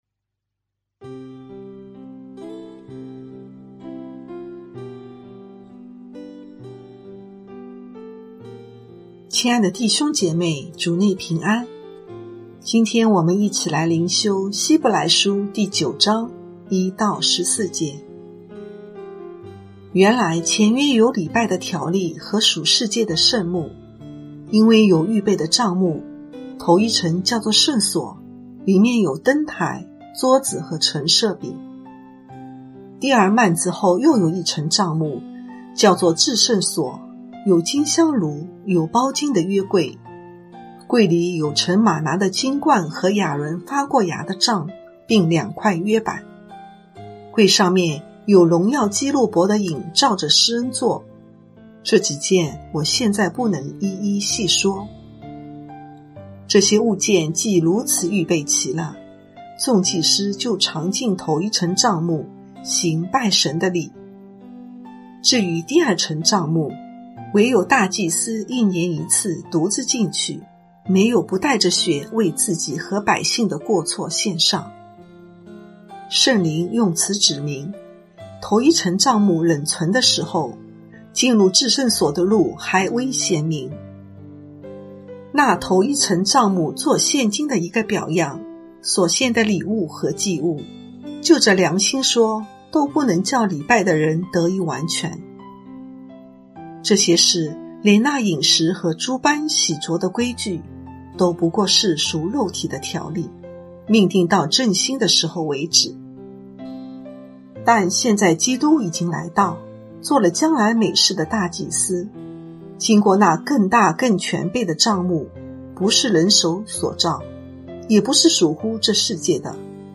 牧長同工分享：神與人同住